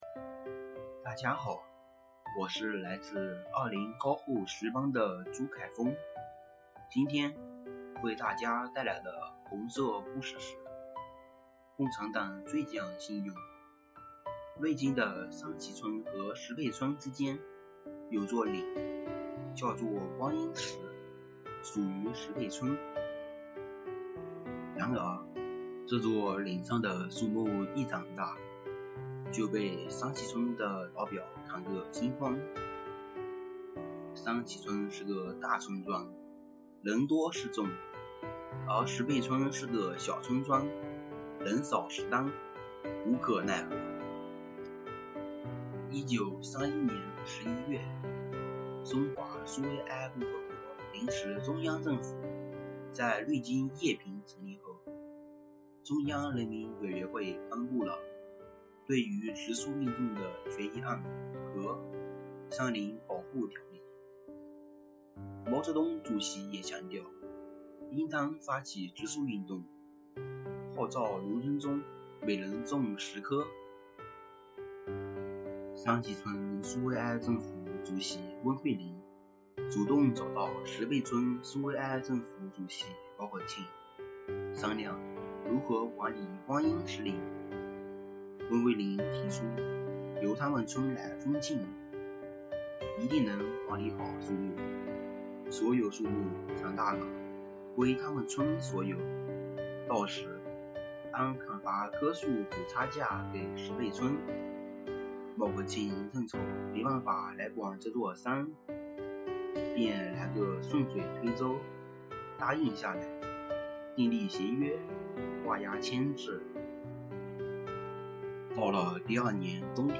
朗读者